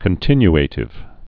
(kən-tĭny-ātĭv, -ə-tĭv)